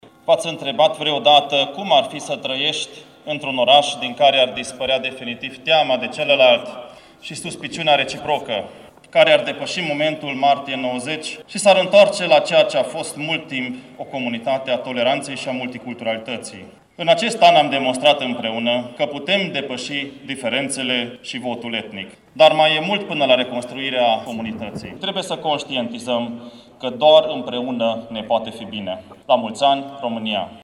Ziua Națională, sărbătorită la Tg. Mureș
Primarul municipiului Târgu Mureș, Soós Zoltán, a făcut apel la unitate pentru vindecarea rănilor trecutului: